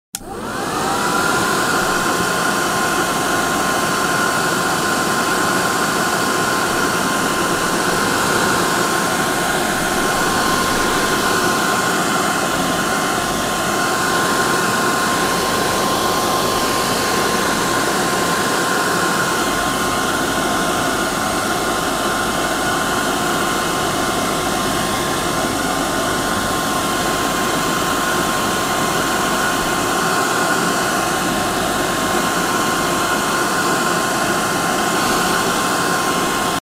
Звук вентилятора фена